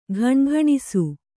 ♪ ghaṇaghaṇisu